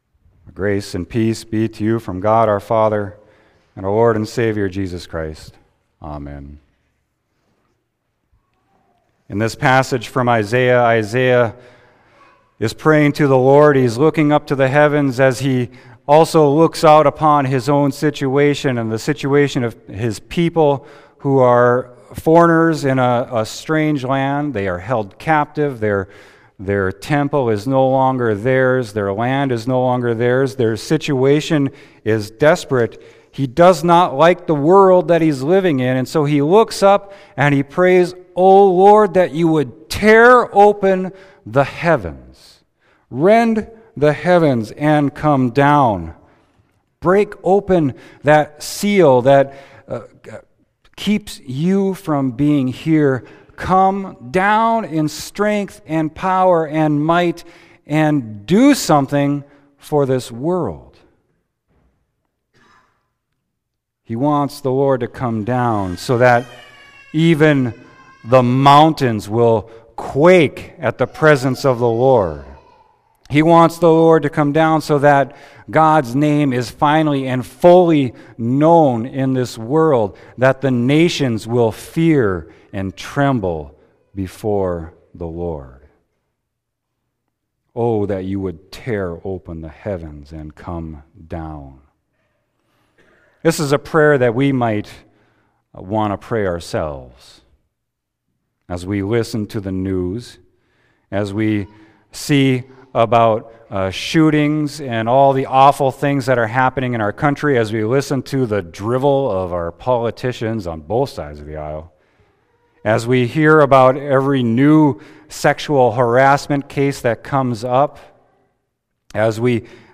Sermon: Isaiah 64.1-9